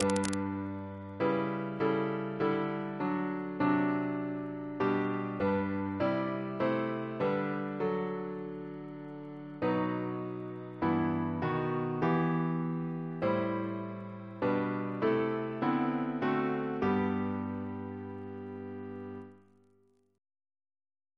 Double chant in G Composer: Chris Biemesderfer (b.1958)